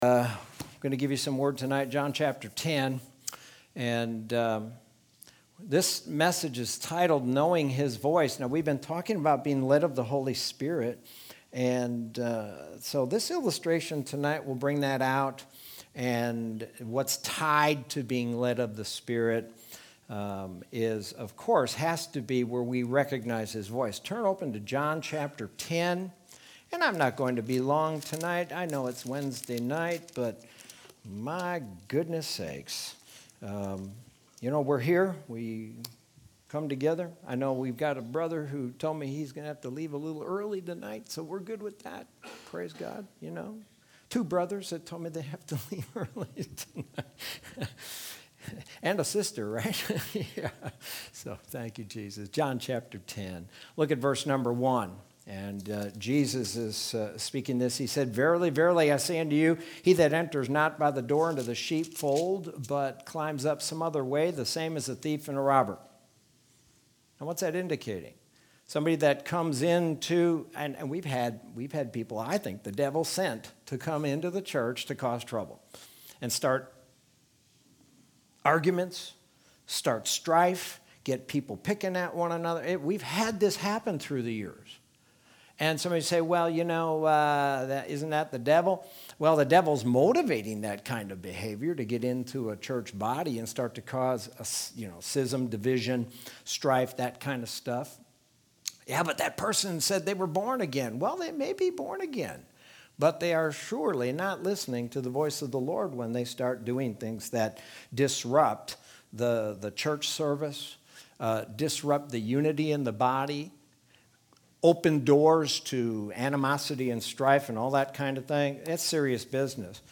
Sermon from Wednesday, September 30th, 2020.